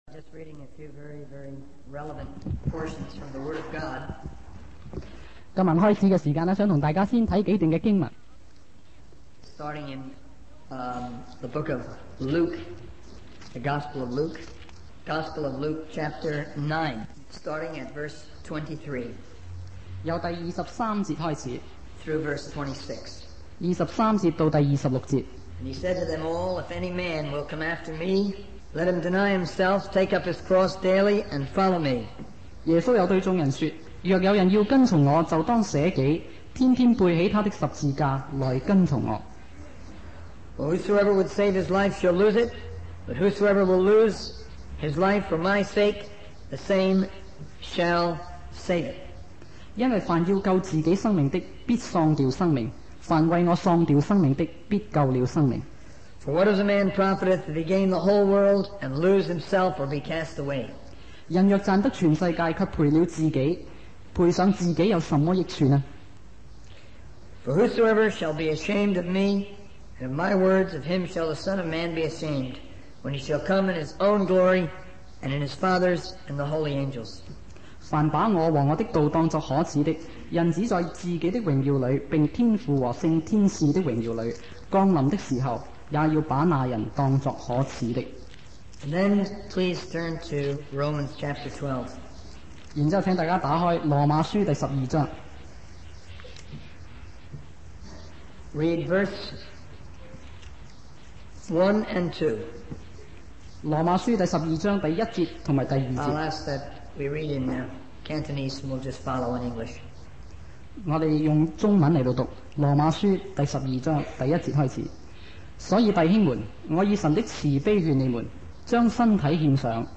In this sermon, the speaker discusses the history and impact of a ship that was used for missionary work.